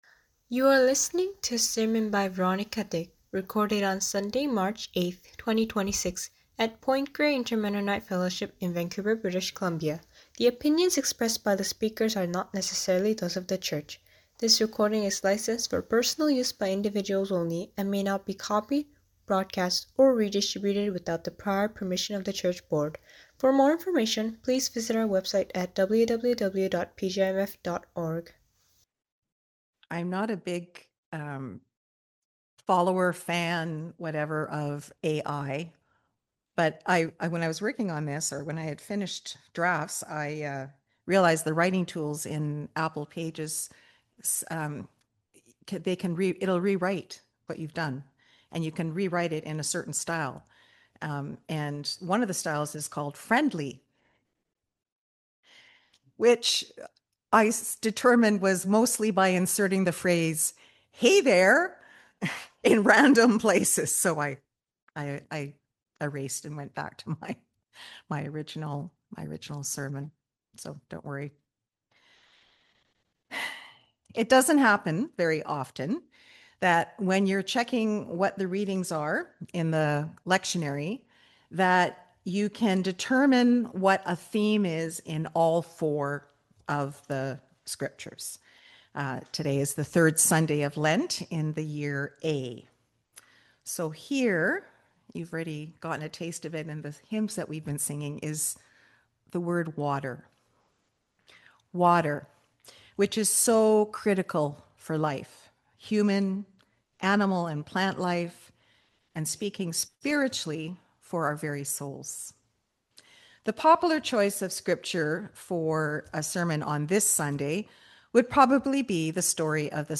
Sermon Recording: Download